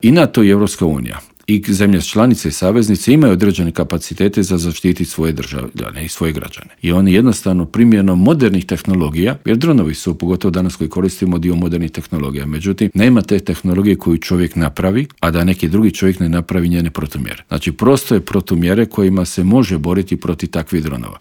Dio je to tema o kojima smo u Intervjuu Media servisa razgovarali